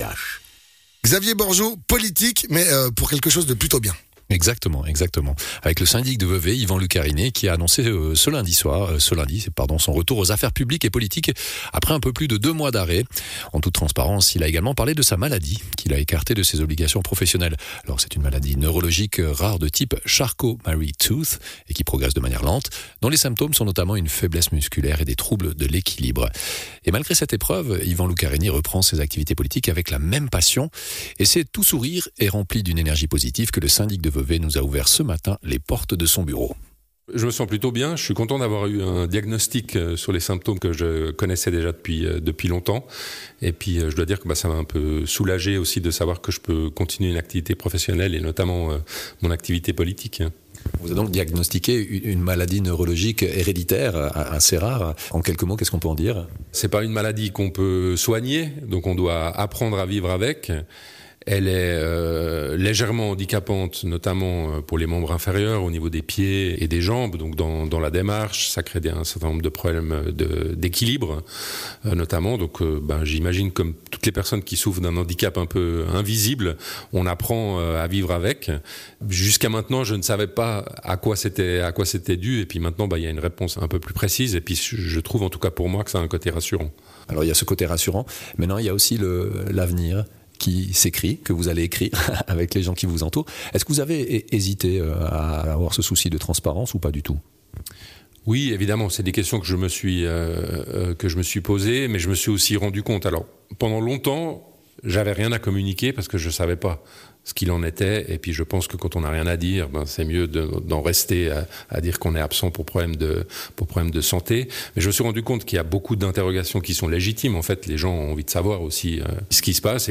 Intervenant(e) : Yvan Luccarini, Syndic de Vevey